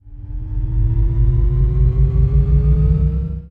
acceleration.ogg